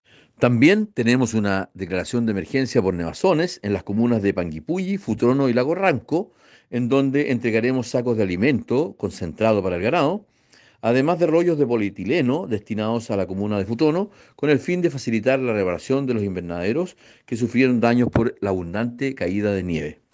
La autoridad agregó que, además de mitigar los efectos del alto precio de los fertilizantes, también se brindará apoyo a los pequeños productores agrícolas afectados por los problemas climáticos: